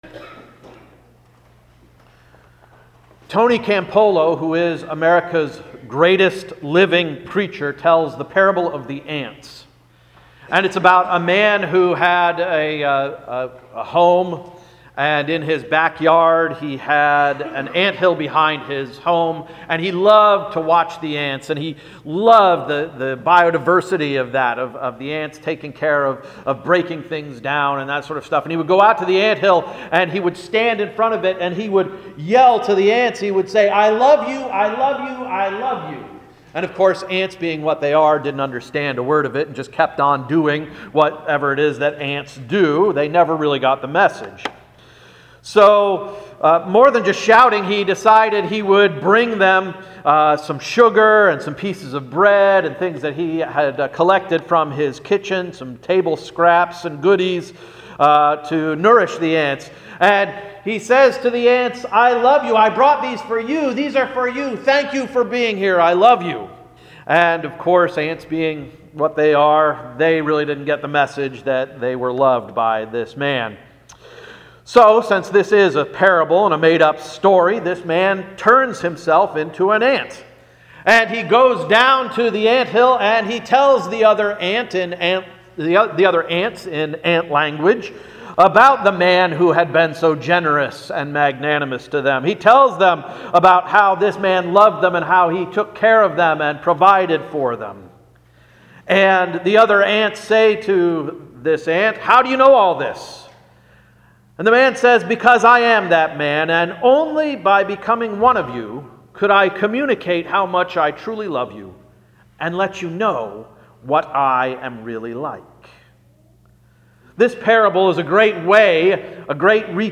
March 4, 2018 Sermon — “Plant a Seed”